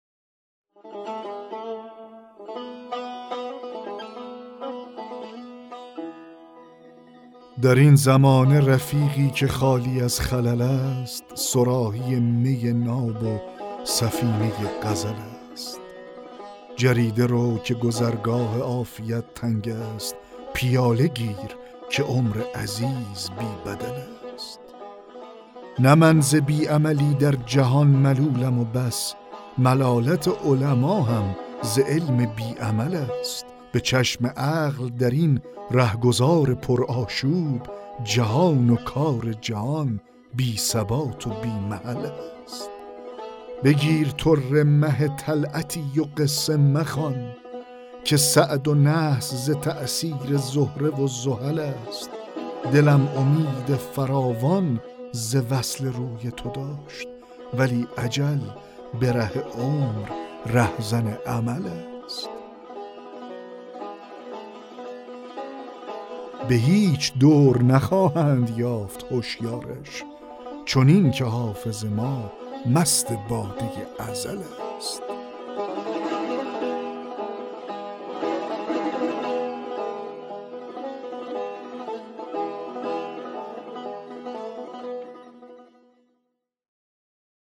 دکلمه غزل 45 حافظ
دکلمه-غزل-45-حافظ-در-این-زمانه-رفیقی-که-خالی-از-خلل-است.mp3